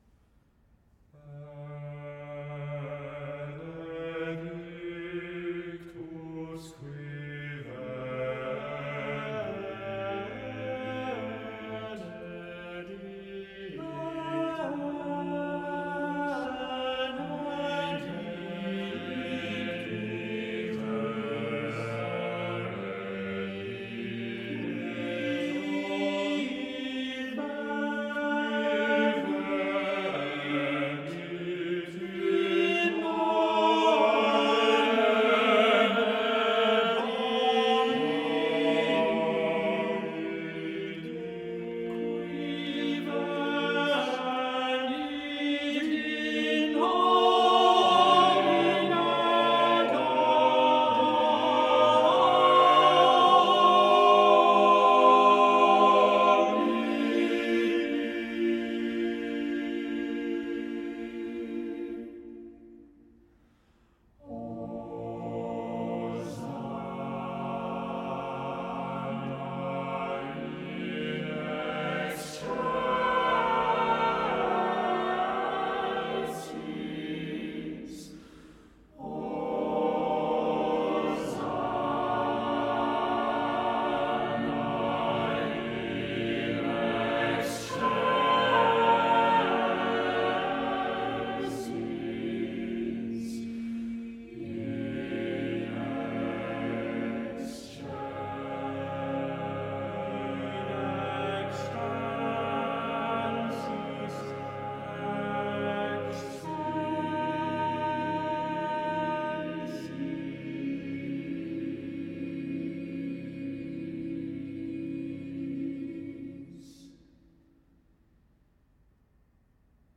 Mass